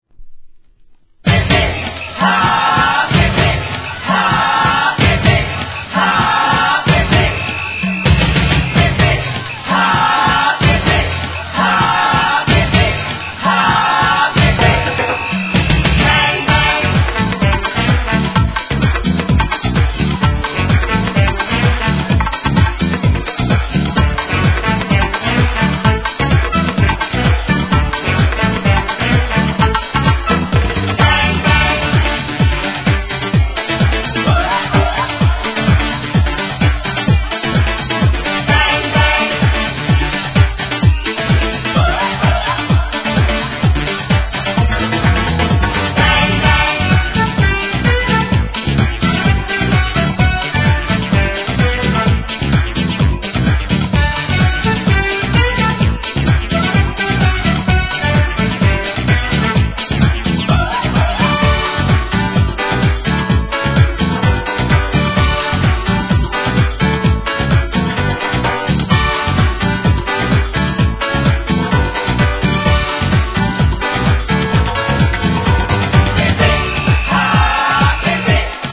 店舗 ただいま品切れ中です お気に入りに追加 LATIN JAZZ CLASSICをテンション↑↑でREMIX!!!
CLUB MIX